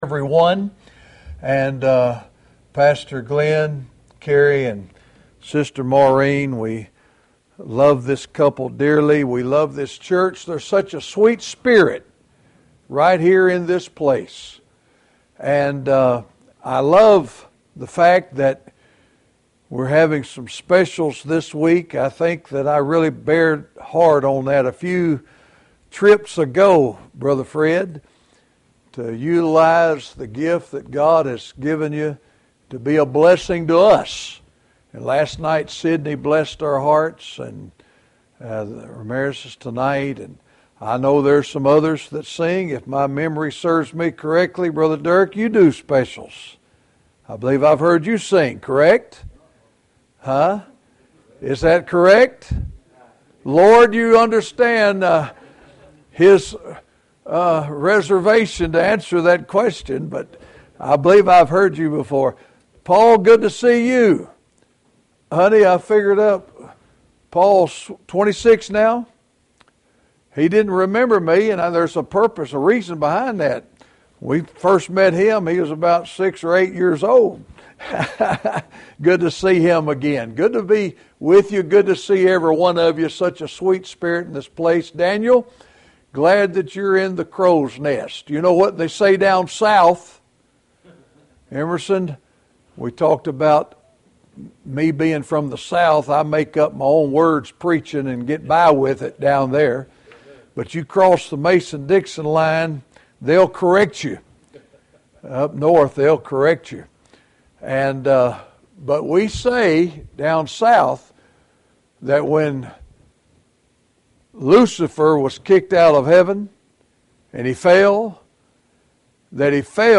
Passage: II Kings 4:17-26 Service Type: Monday Evening http